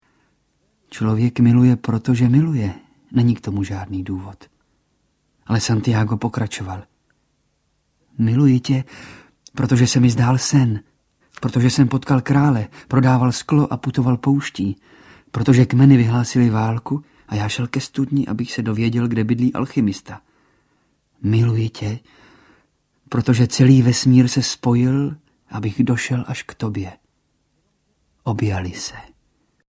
Nejen růže audiokniha
Zvukový portrét herce Jana Potměšila.
Ukázka z knihy